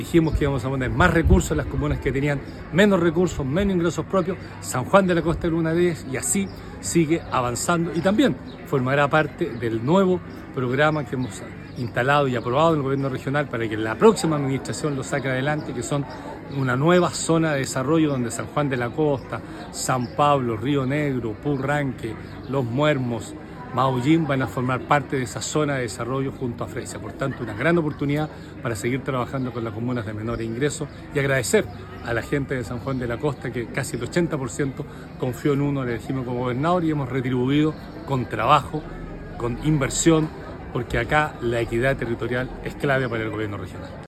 Estas iniciativas que benefician a las comunidades, se buscará seguir implementando en las distintas localidades de la Región de Los Lagos, enfatizó el Gobernador Regional.